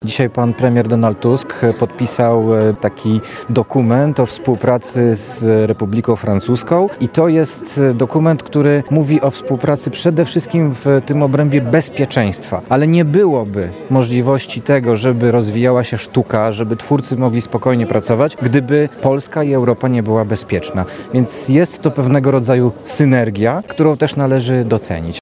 – Podpisanie polsko-francuskiego traktatu o wzmocnionej współpracy i przyjaźni pokazuje, że Europa potrafi się jednoczyć – skomentował w piątek (09.05) w Lublinie Jakub Stefaniak (na zdj.), zastępca szefa Kancelarii Prezesa Rady Ministrów.